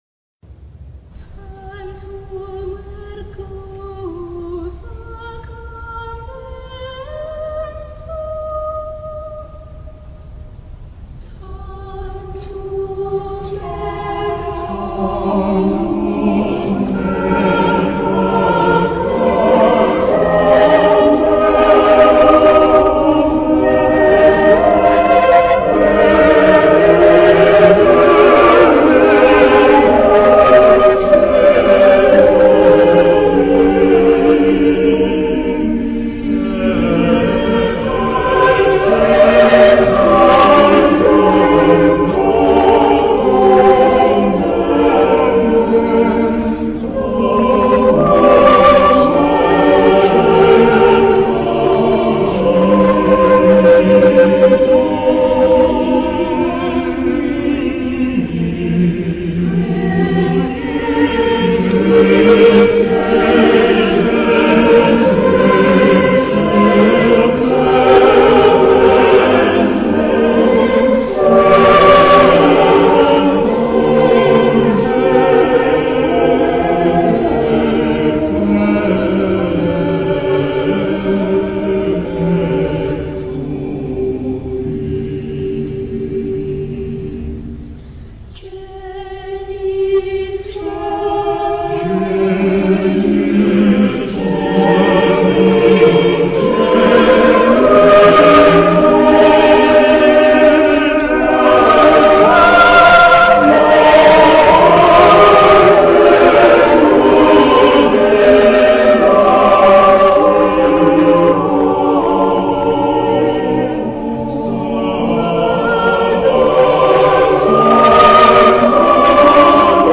TANTUM ERGO SACRAMENTUM - CANTOS GREGORIANOS E DE CORAIS.mp3